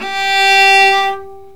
Index of /90_sSampleCDs/Roland L-CD702/VOL-1/STR_Viola Solo/STR_Vla3 Arco nv
STR VIOLA 0D.wav